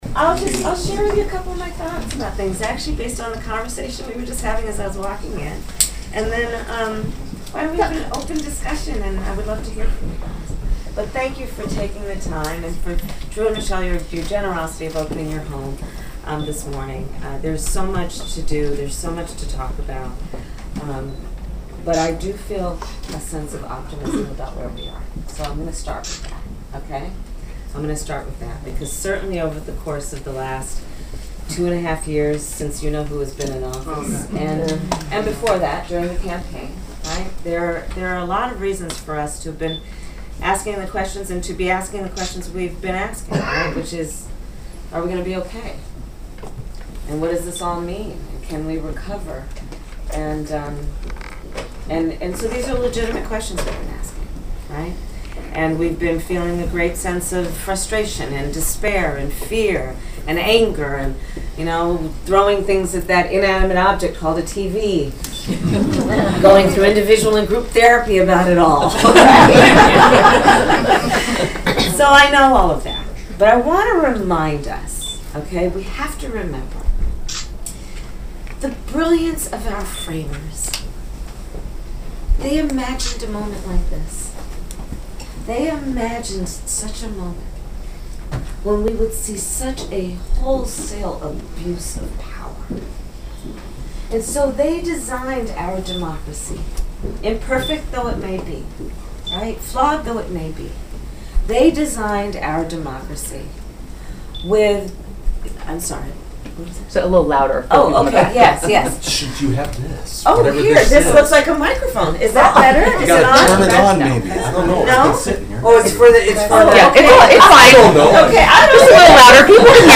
Kamala Harris holds community conversation with citizens at a Clinton home
KROS News Hear her remarks